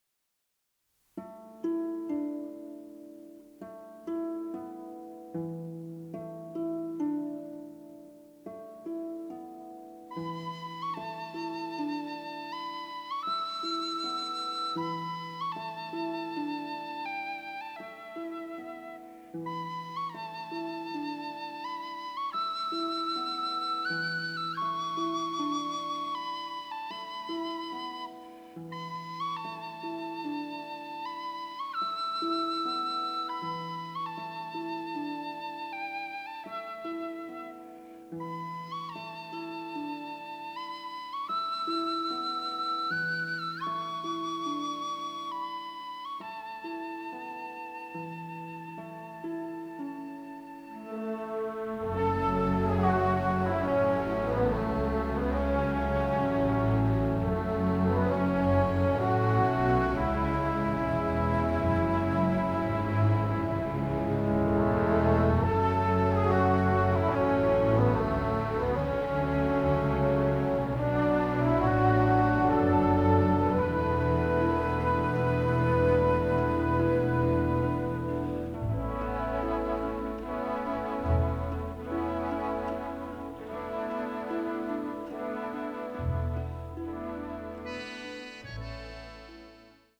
a sensitive, dramatic, delicate score with an Irish flavor